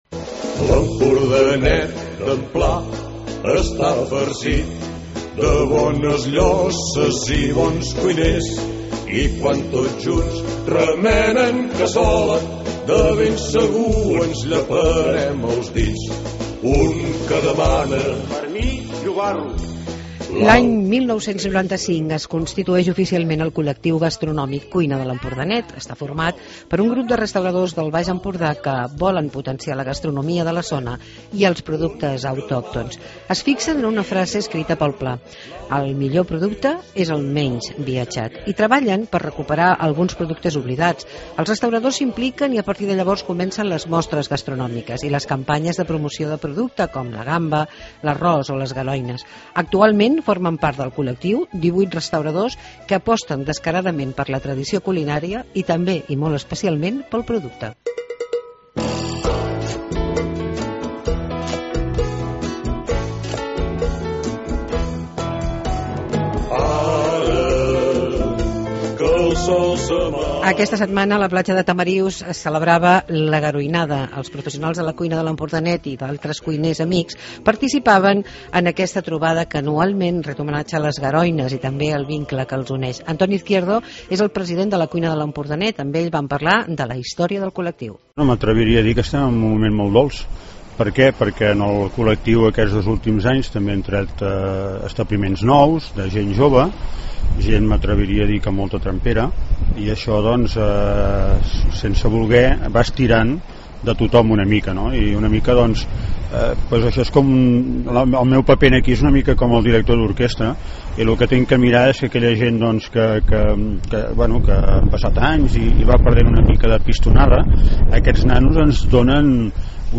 Reportatge de La Garoinada de Tamariu